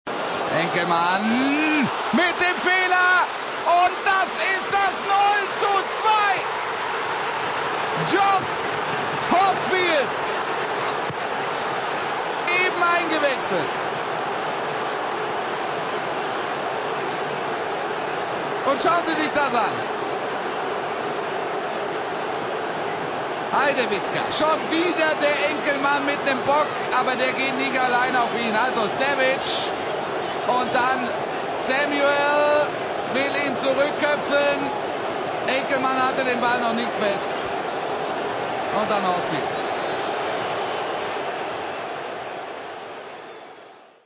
[01h38] TV_Sport -- Und hier wie versprochen, die Files: Wolff Fuss zum 0:1 (260kB, MP3), Wolff Fuss zum 0:2 (